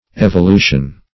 Evolution \Ev`o*lu"tion\ ([e^]v`[-o]*l[=u]"sh[u^]n), n. [L.